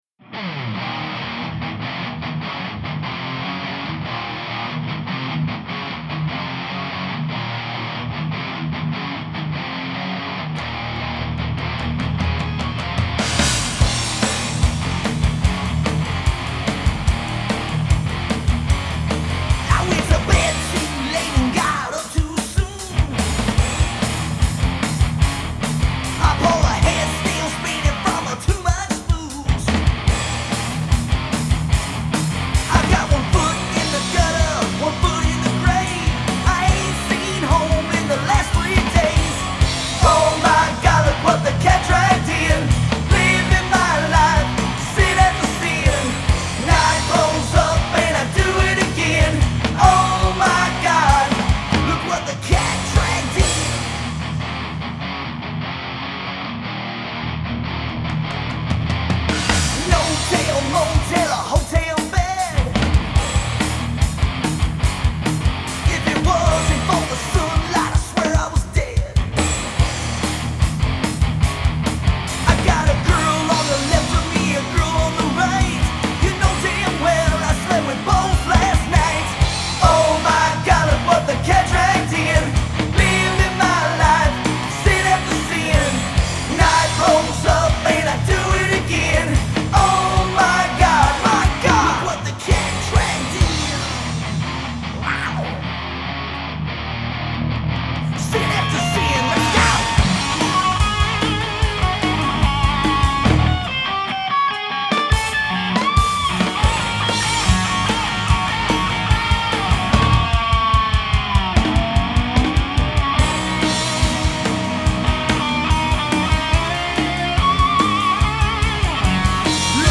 Live in St. Louis.